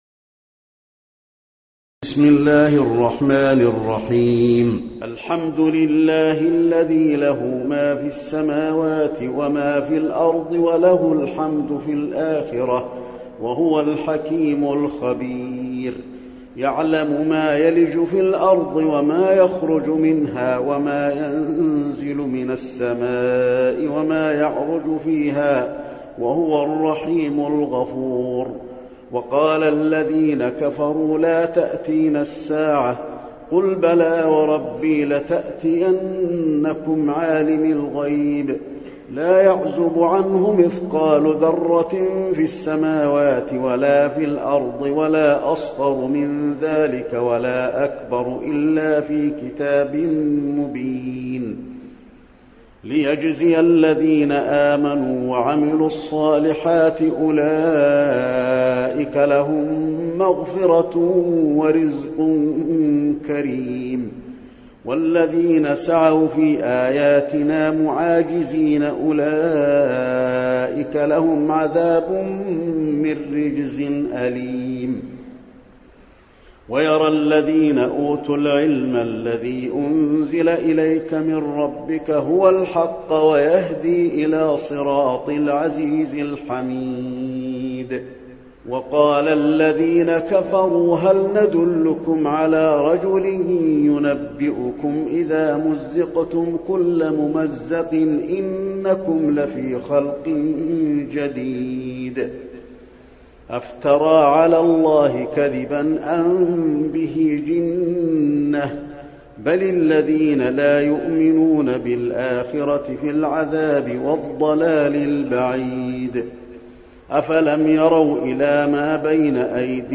المكان: المسجد النبوي سبأ